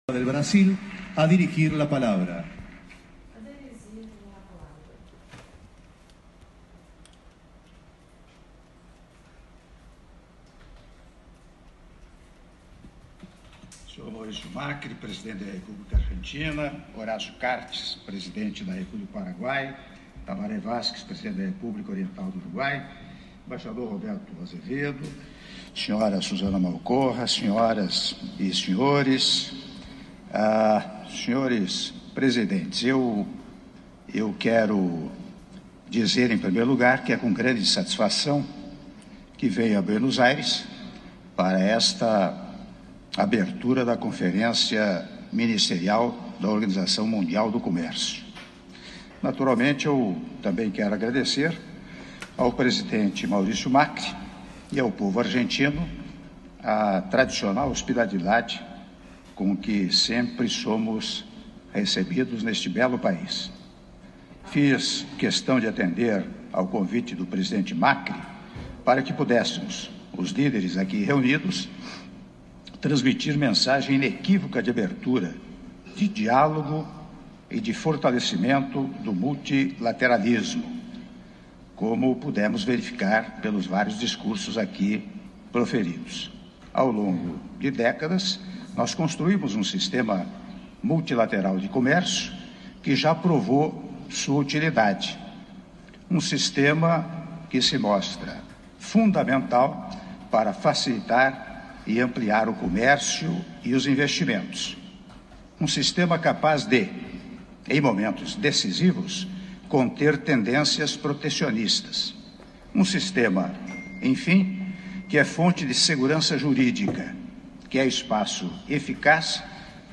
Áudio do discurso do Presidente da República, Michel Temer, durante sessão de abertura da XI Conferência Ministerial da OMC - Buenos Aires-Argentina (05min07s)